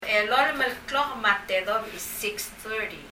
発音
発音を聴くと、me a tedobech で「マテド」と聞こえます。
tedobech [tɛðɔb ? ] の後半[b ? ]はほとんど聞こえません。